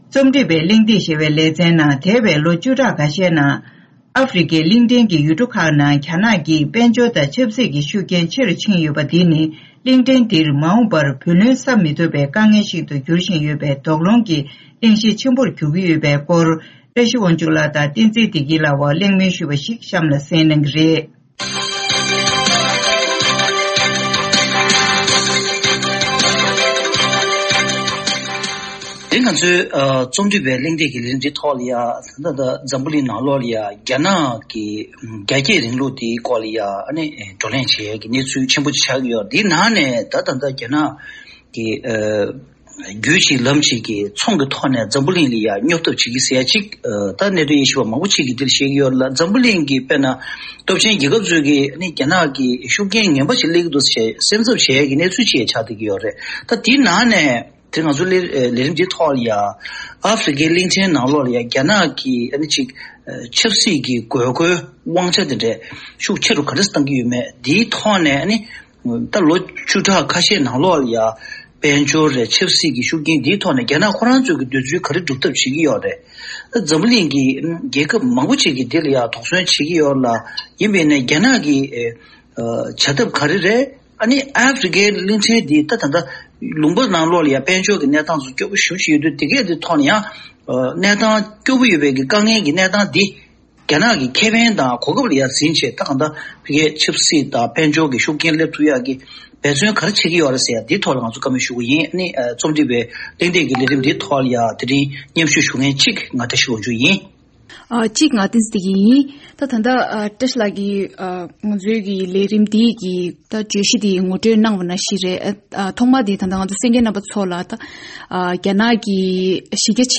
གྲོས་བསྡུར་ཞུས་པའི་ལས་རིམ།